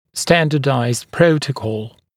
[‘stændədaɪzd ‘prəutəkɔl][‘стэндэдай’зд ‘проутэкол]стандартизированный протокол